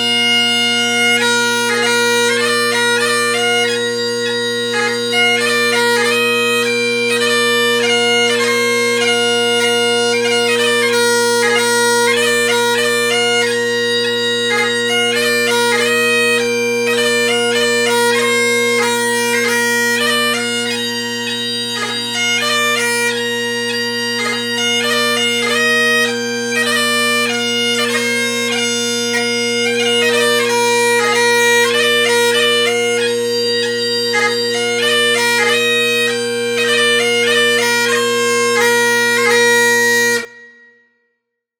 Halifax Bagpiper